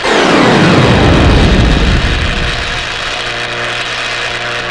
05081_Sound_MUERTECYBORG.mp3